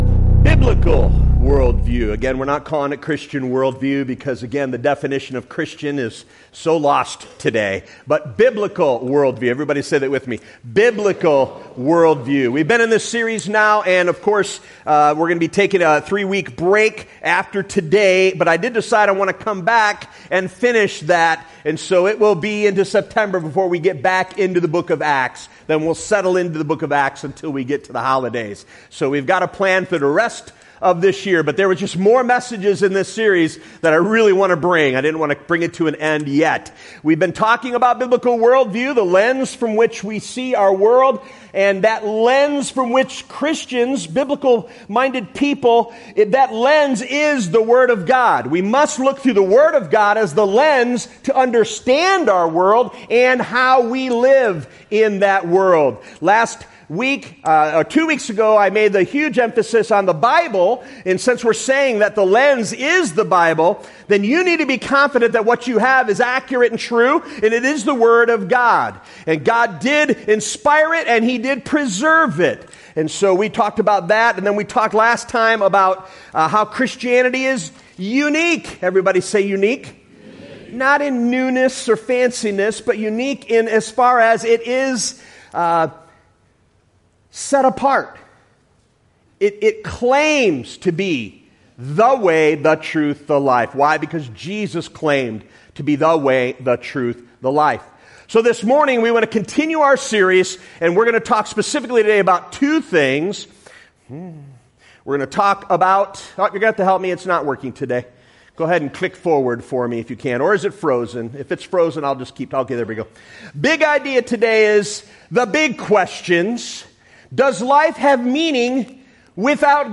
2025 Current Sermon Does Life Have Meaning Without God?